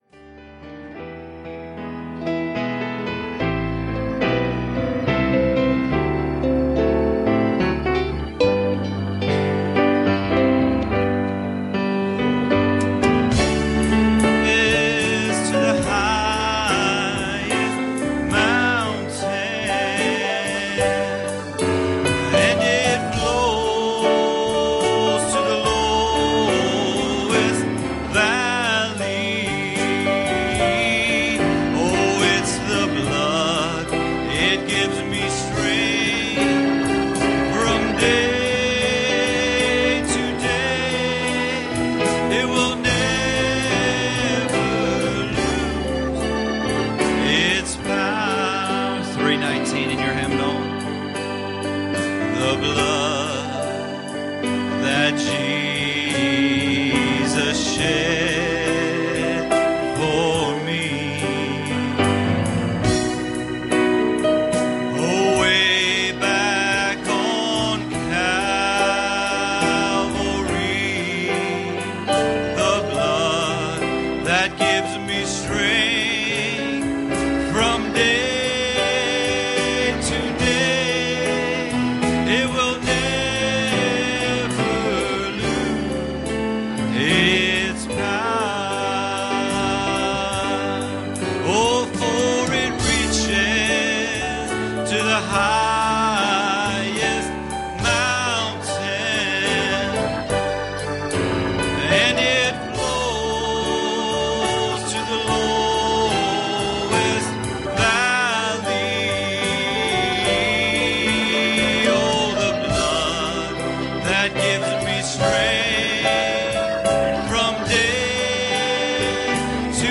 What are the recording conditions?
Passage: 2 Timothy 2:12 Service Type: Sunday Morning